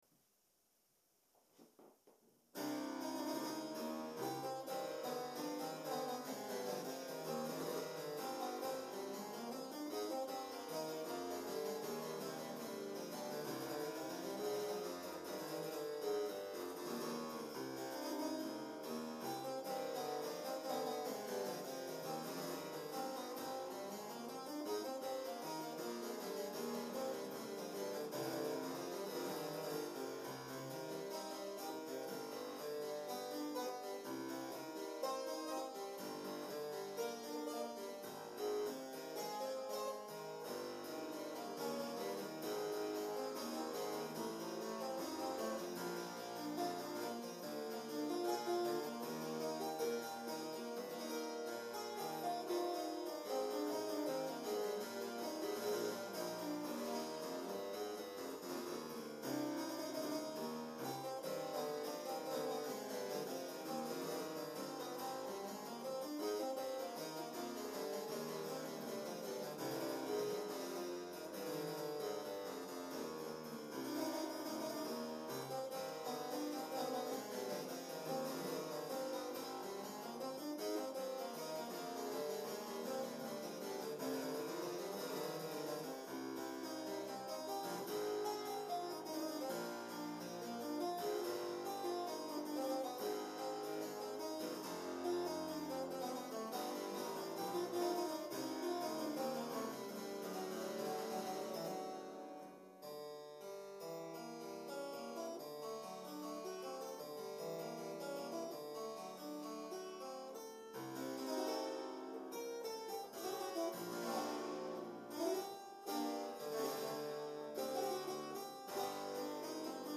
clavecimbel